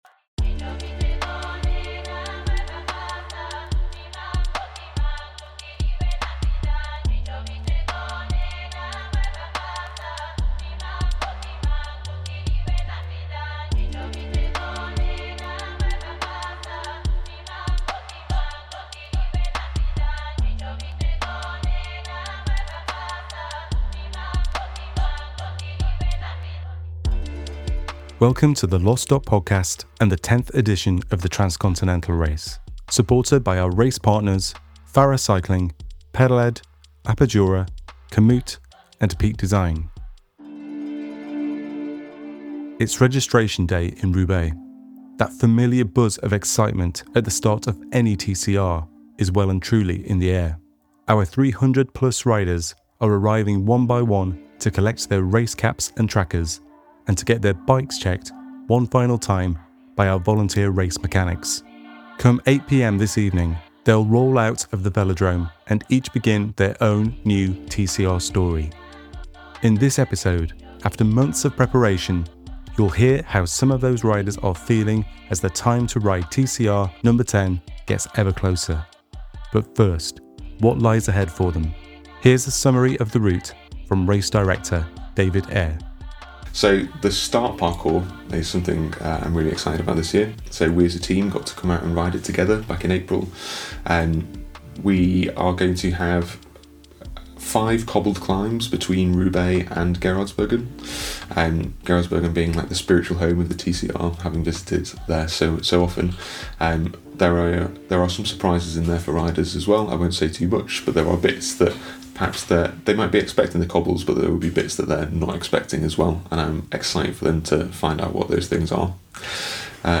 Race Reporters catch up with Organisers and riders as the anticipation mounts and everyone prepares for the Race to begin.
as bells are rung and cheers are shouted by an excited crowd of supporters.